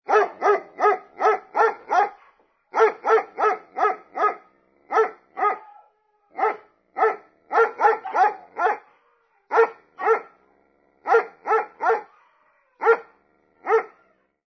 Descarga de Sonidos mp3 Gratis: perro 5.